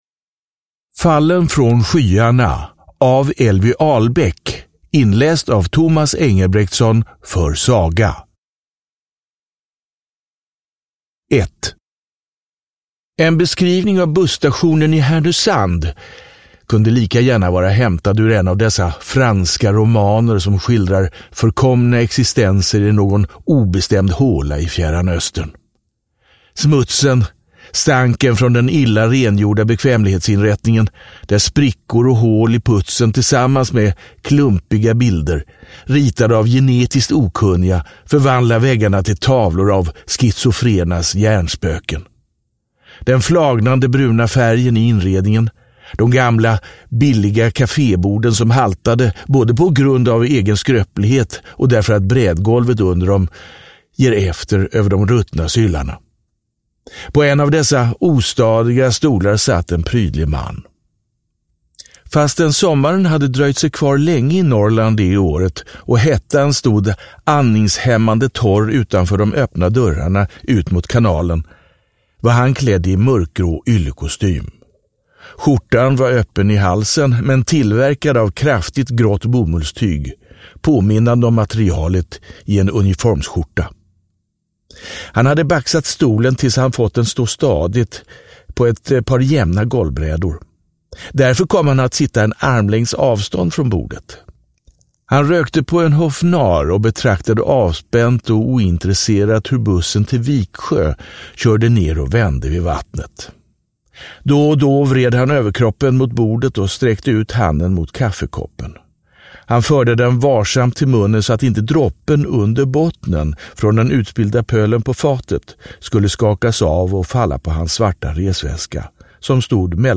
Fallen från skyarna – Ljudbok – Laddas ner